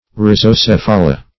Search Result for " rhizocephala" : The Collaborative International Dictionary of English v.0.48: Rhizocephala \Rhi`zo*ceph"a*la\, n. pl.